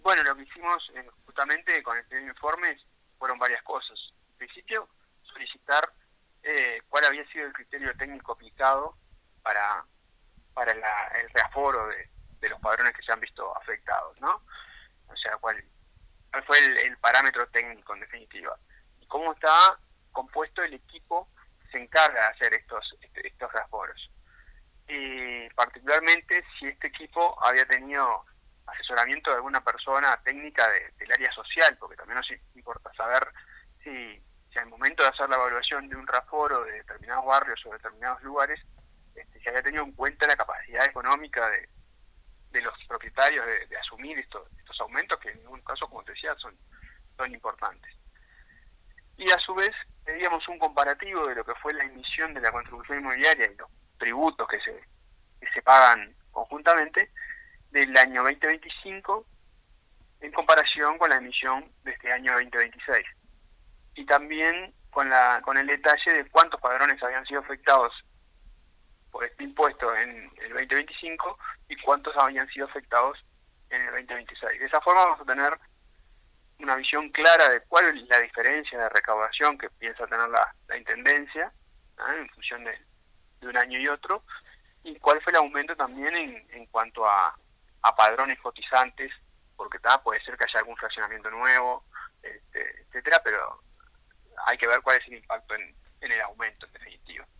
El edil Pablo Cicero dijo a Radio Uruguay que el aumento superó con creces el 4,09 % imponible por IPC que el oficialismo  había manejado en la discusión presupuestal en la Junta Departamental, cuando se debatía la creación de la tasa ambiental en sustitución del impuesto general municipal.